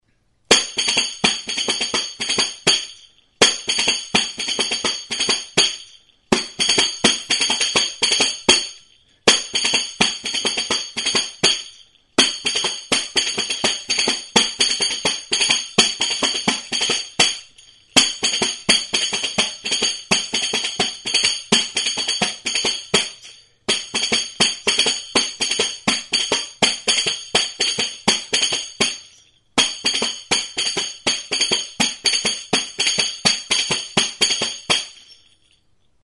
Music instrumentsPANDEROA
Membranophones -> Beaten -> Tambourines
Recorded with this music instrument.
Bi lerrotan jarririk metalezko 9 txinda pare ditu.